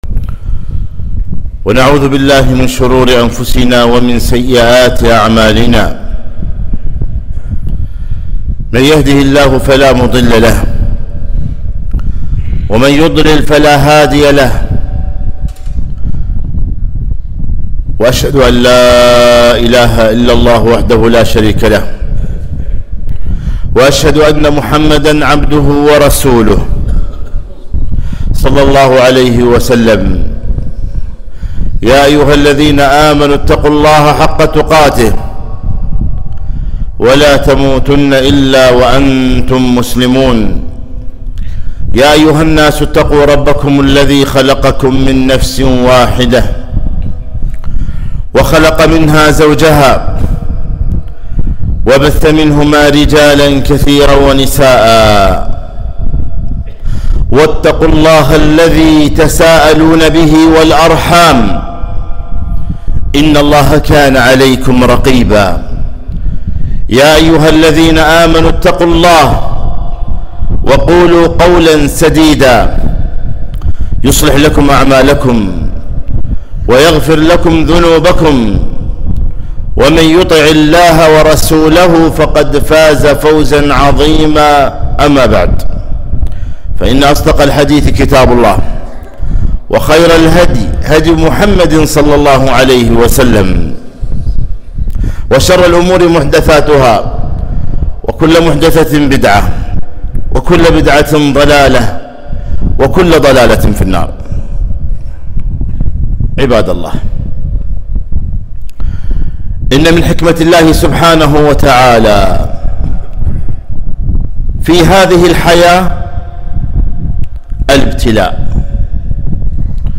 خطبة - (ونبلوكم بالشر والخير فتنة)